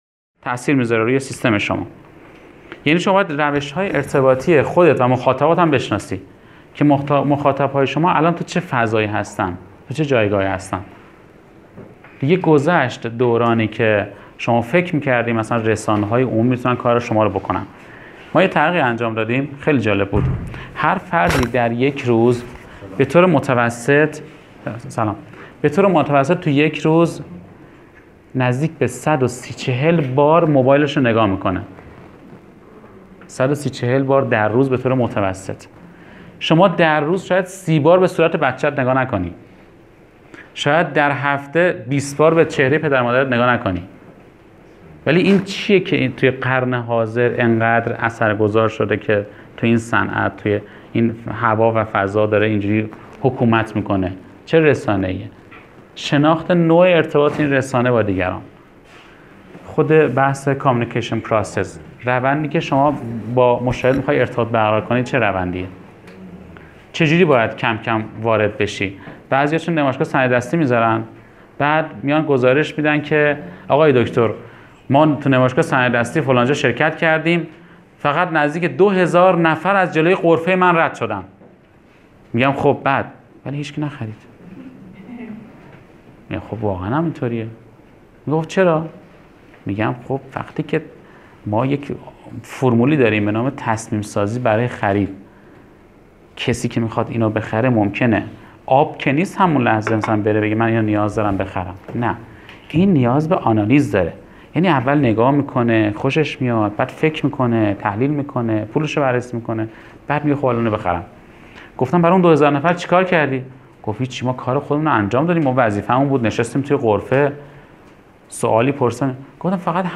فایل صوتی دوره ی بازاریابی و برندسازی در صنایع دستی و محصولات فرهنگی برای مدیران، مسئولین و پرسنل س...